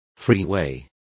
Complete with pronunciation of the translation of freeways.